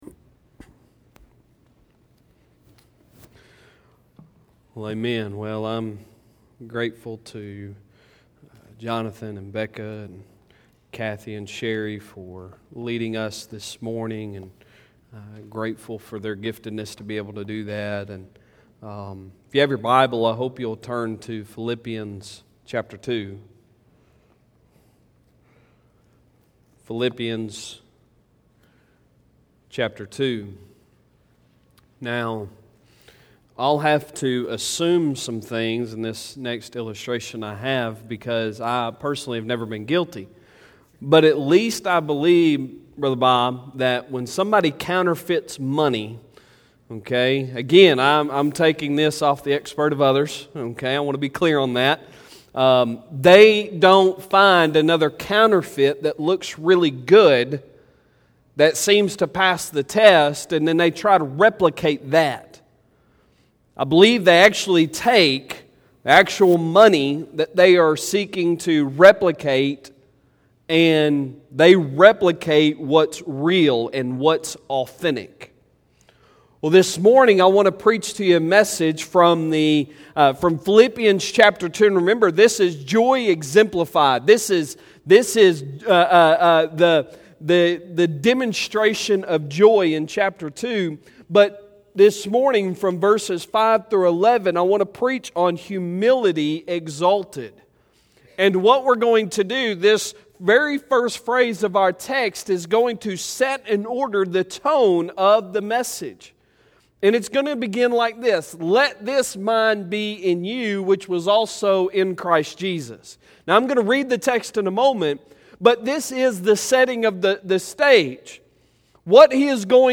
Sunday Sermon June 21, 2020